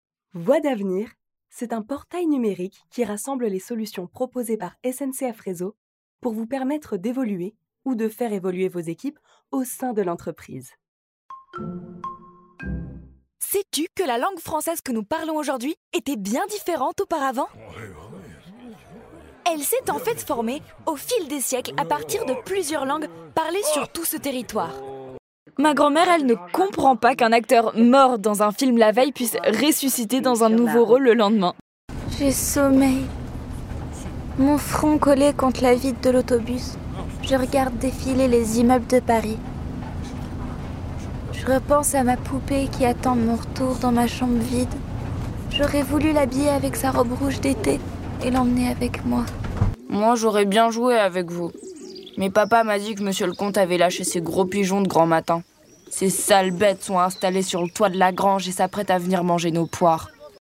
Bande-Démo FR